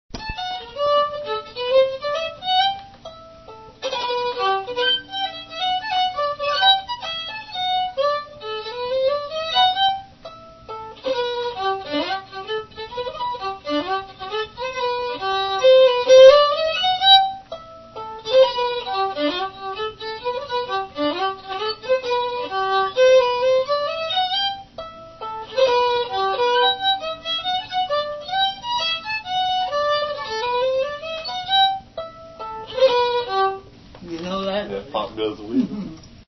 fiddle
Key: G
Meter: 6/8
Jigs
Fiddle tunes
Instrumentals--fiddle